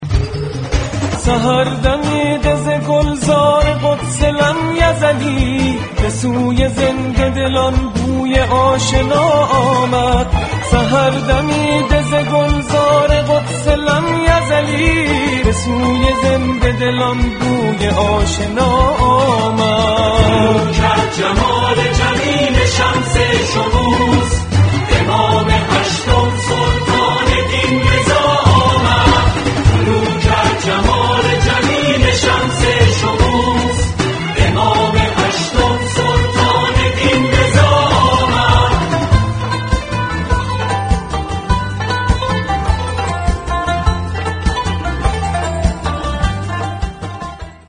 زنگ موبایل
(با کلام)